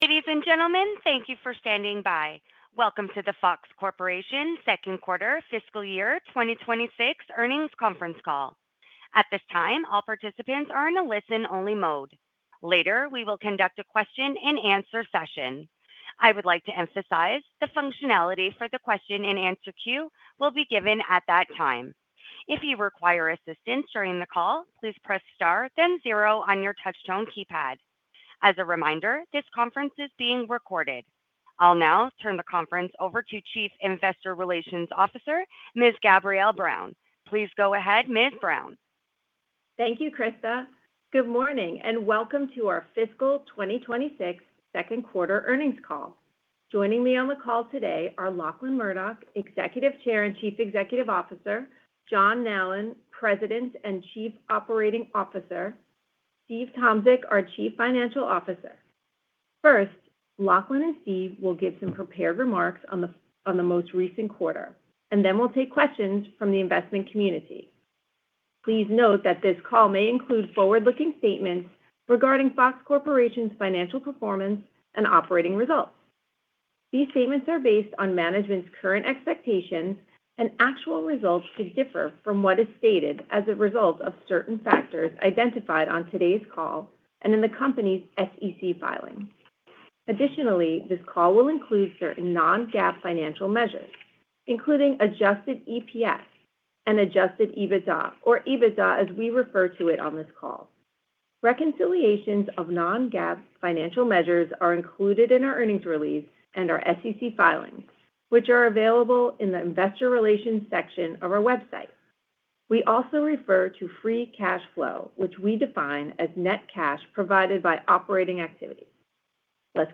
FOX-FY2026-Q2-Earnings-Call_Event-Recording.mp3